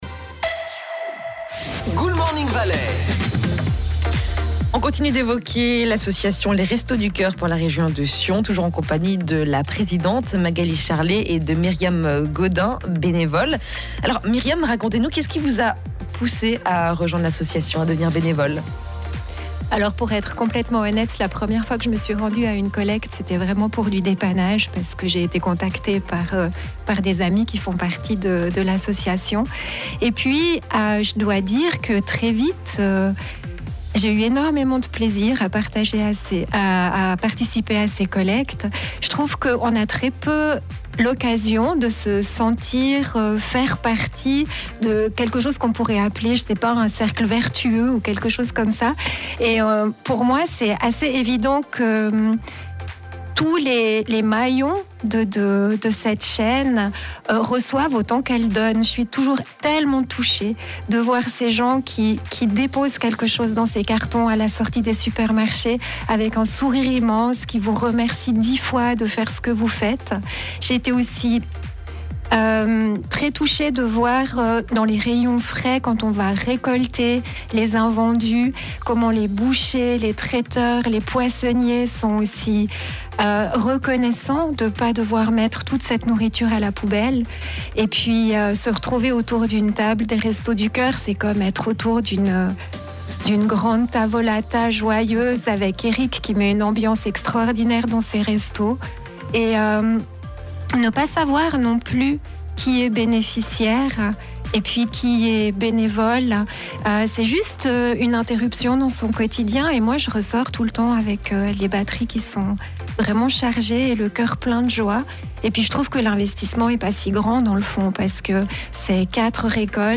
Partie 2 de l’interview